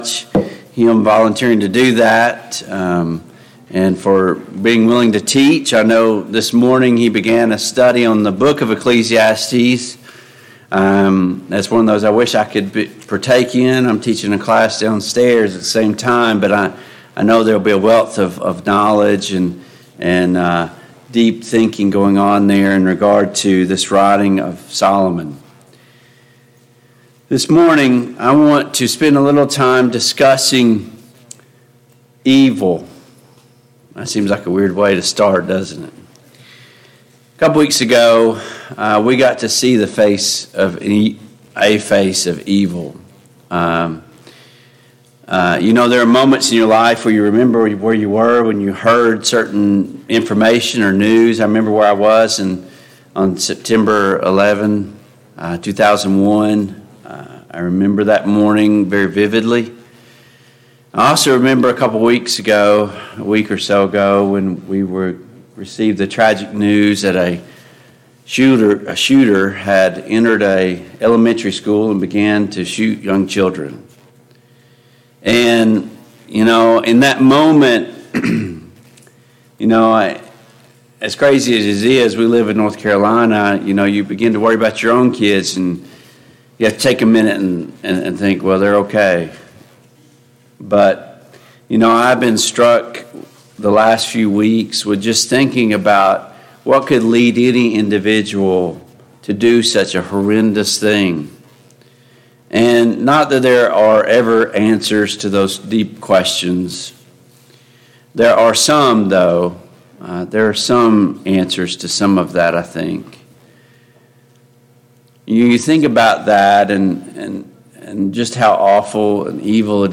Service Type: AM Worship Topics: Overcoming Temptation and Sin , Sin , Temptation